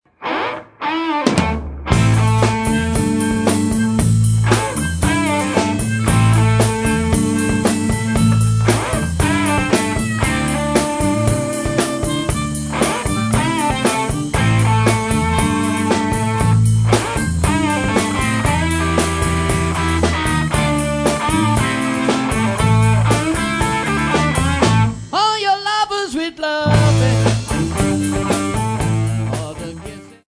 Some clips from the rehersals..